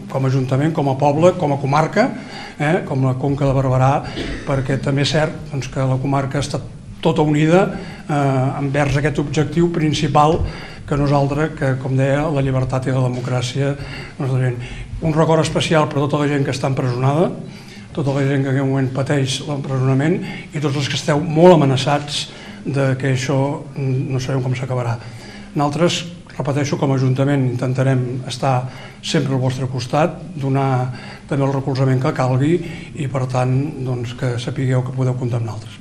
L’alcalde de l’Espluga, David Rovira, també ha aprofitat l’oportunitat per traslladar el suport del consistori espluguí als polítics catalans empresonats i als qui encara continuen a Brussel·les.